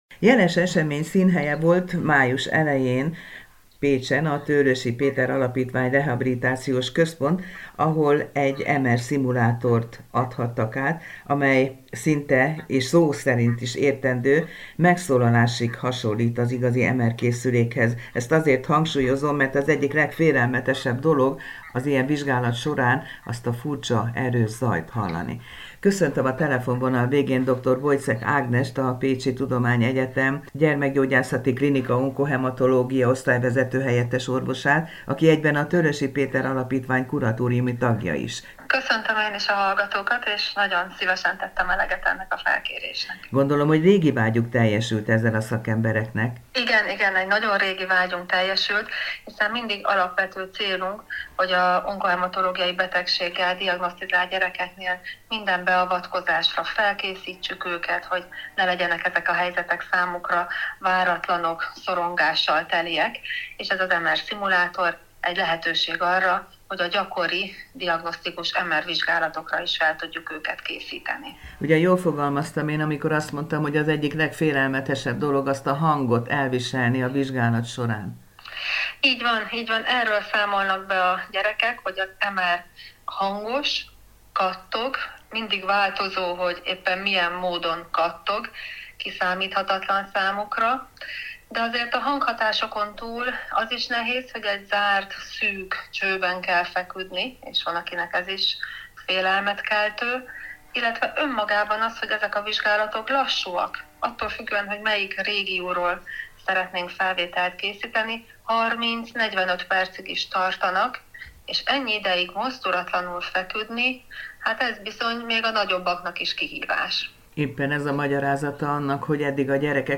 Az újdonságról kérdezte a Csillagpont Rádió műsorában